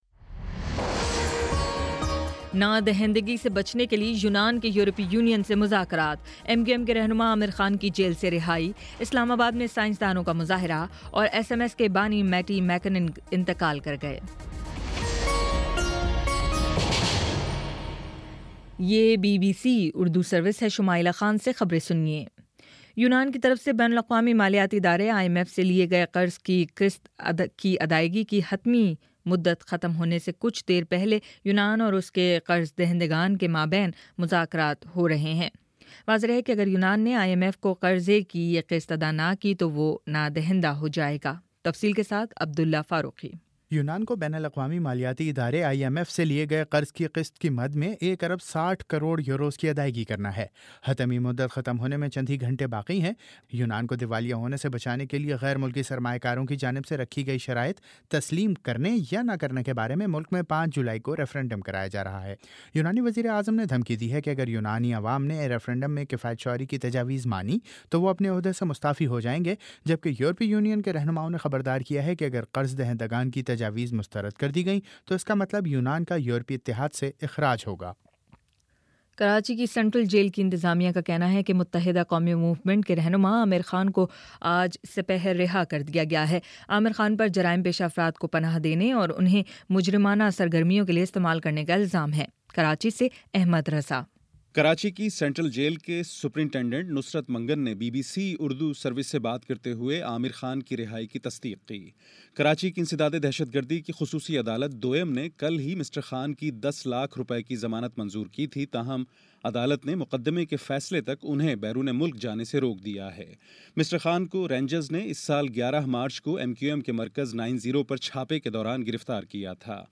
جون 30: شام چھ بجے کا نیوز بُلیٹن